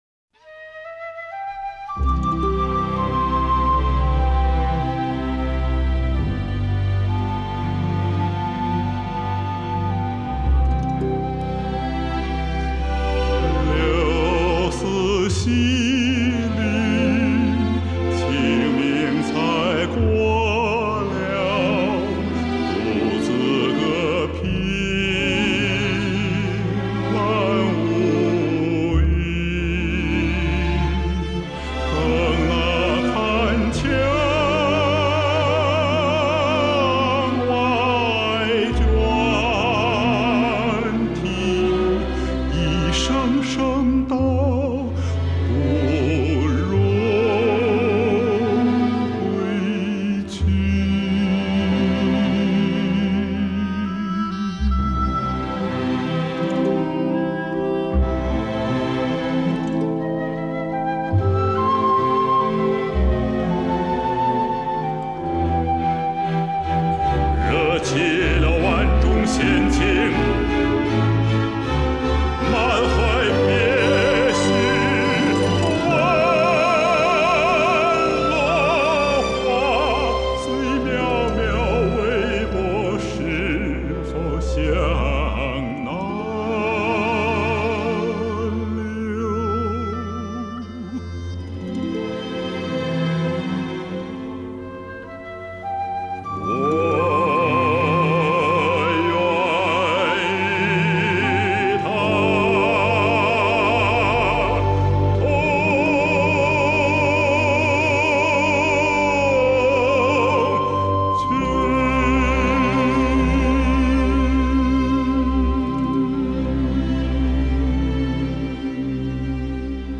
样本格式    : 44.100 Hz; 16 Bit; 立体声